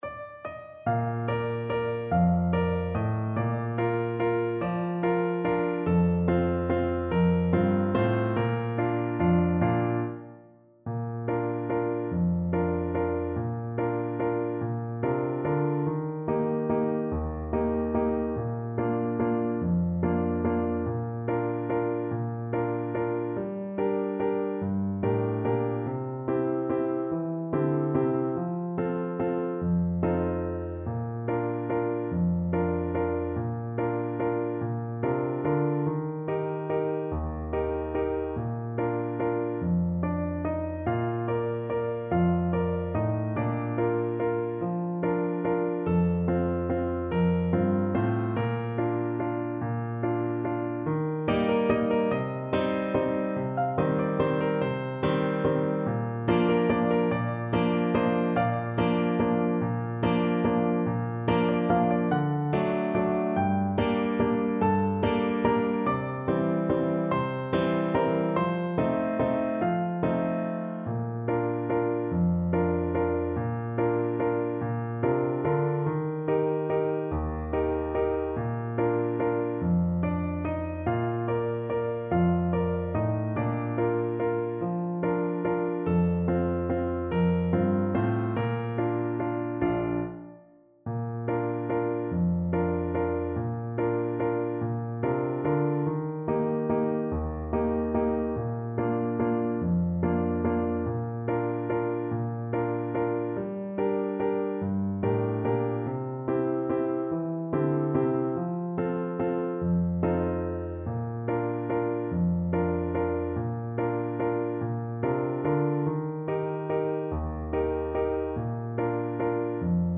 One in a bar .=c.48
3/4 (View more 3/4 Music)
Classical (View more Classical Clarinet Music)